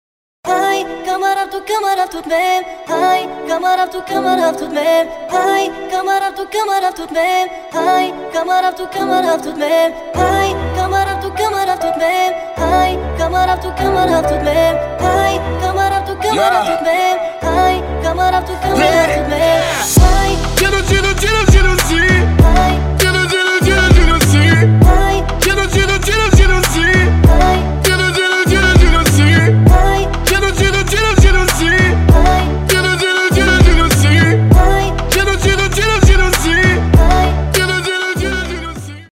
• Качество: 320, Stereo
Хип-хоп
dance
Trap
восточные
Bass